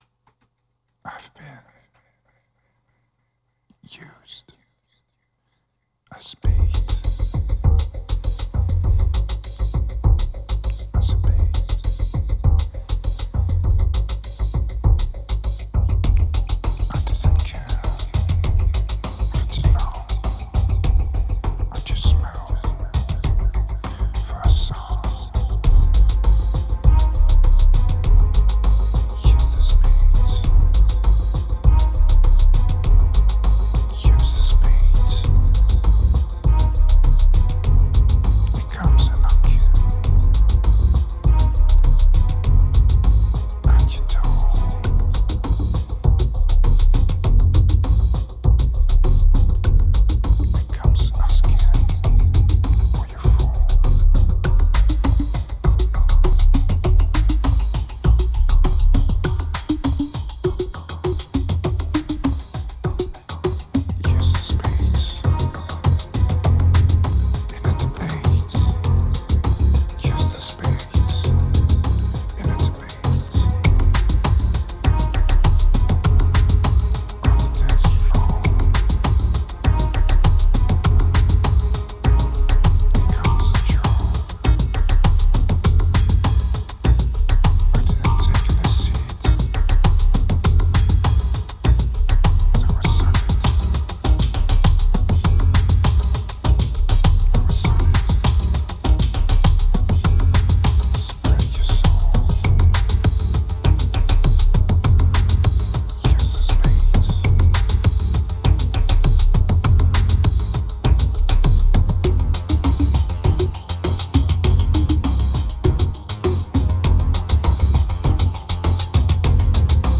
2429📈 - -45%🤔 - 100BPM🔊 - 2010-02-28📅 - -325🌟